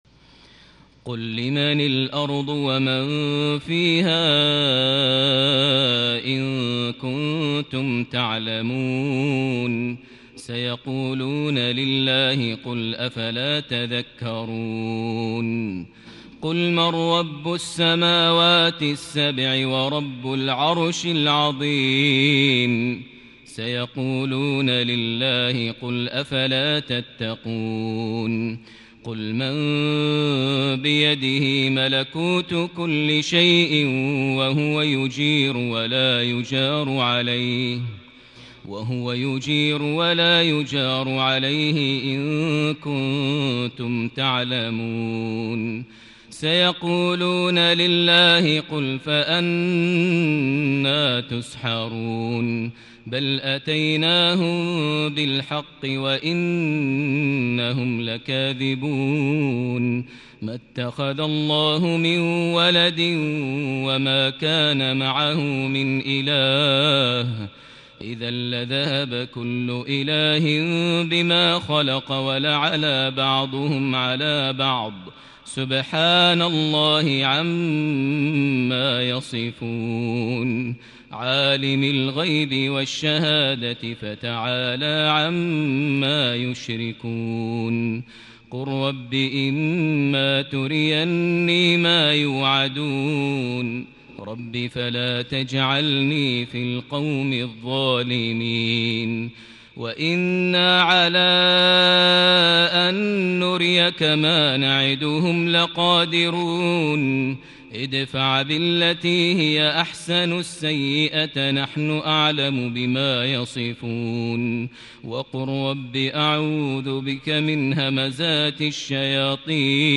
صلاة الفجر ١٥ شوال ١٤٤٠هـ من سورة المؤمنون ٨٤-١١٨ > 1440 هـ > الفروض - تلاوات ماهر المعيقلي